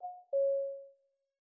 Knock Notification 12.wav